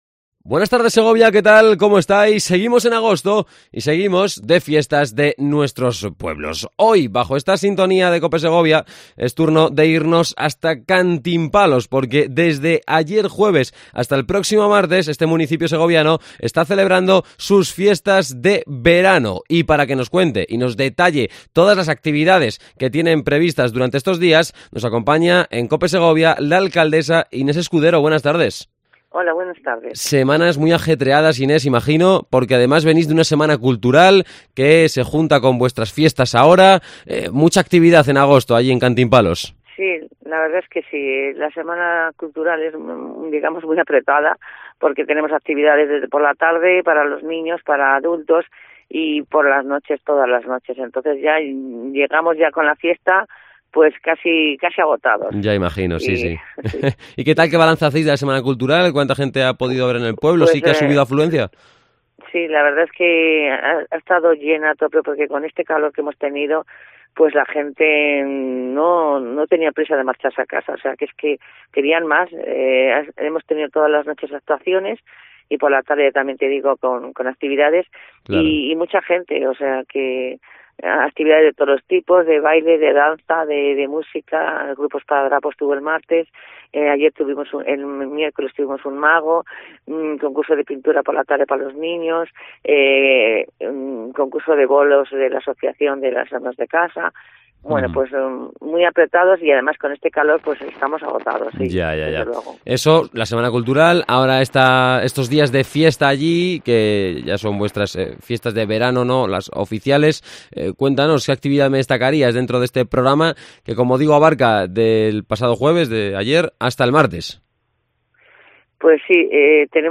Entrevista a Inés Escudero, Alcaldesa de Cantimpalos.